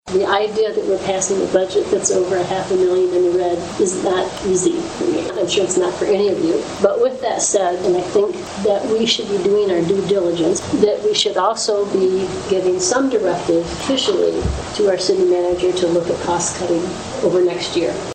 Commissioner Cathi Abbs says the City should be looking at cutting costs.